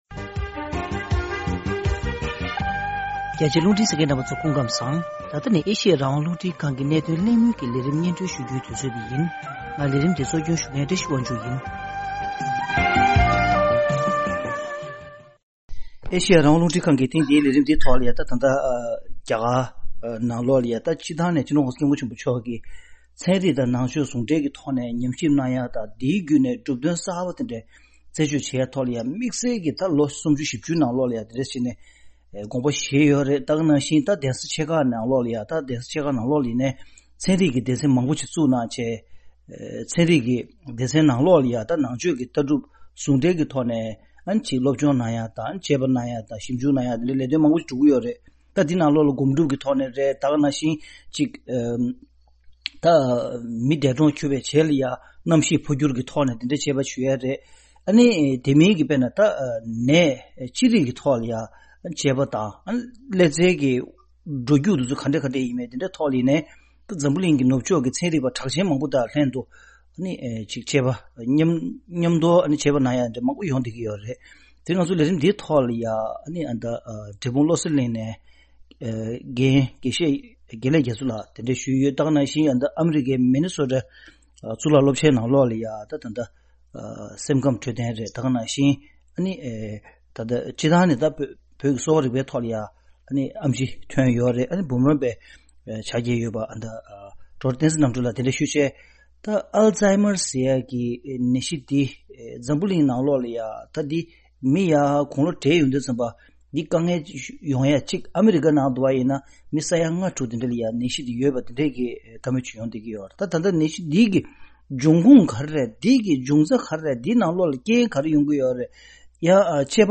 བོད་མིའི་ནང་ཆོས་ཀྱི་བསྟི་གནས་བྱེས་ཀྱི་གདན་ས་ཆེ་ཁག་ཏུ་དྲན་པ་ཉམས་རྒུད་ཀྱི་ནད་གཞིར་ཞིབ་འཇུག་གནང་བཞིན་ཡོད་པའི་ཐད་གླེང་མོལ་ཞུས་པ།